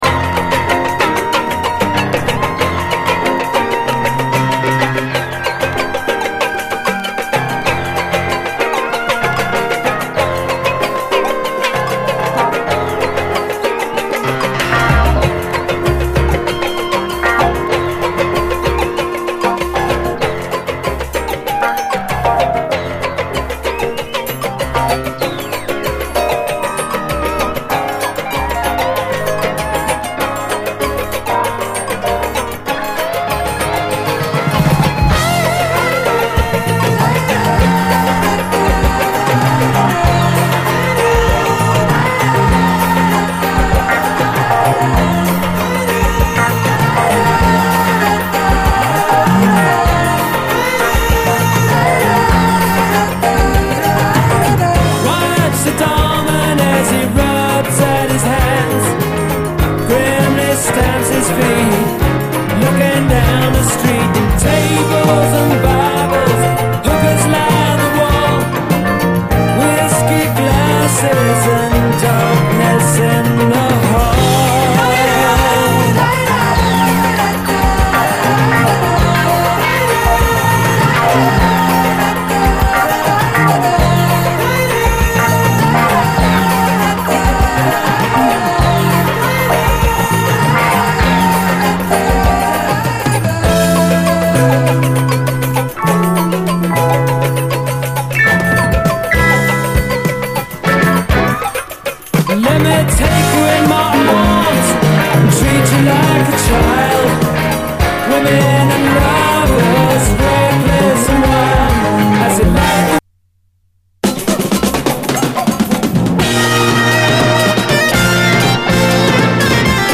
70's ROCK, ROCK